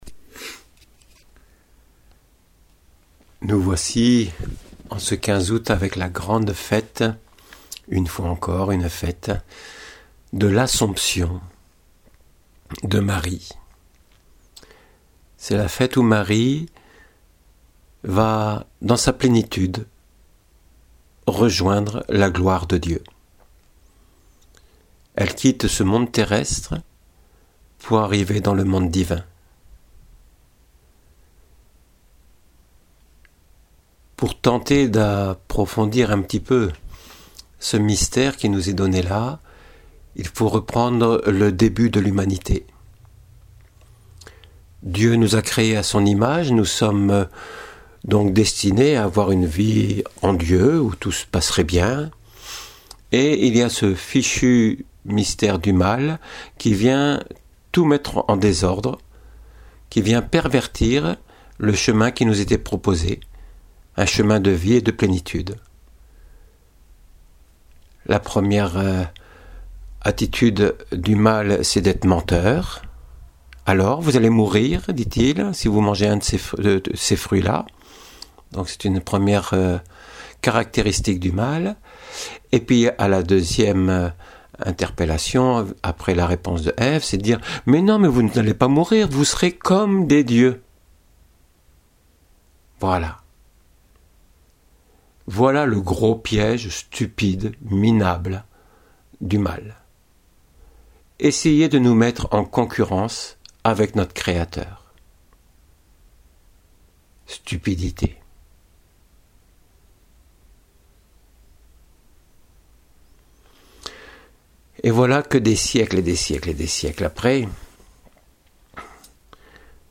homélie Assomption de Marie - Oeuvre du Berceau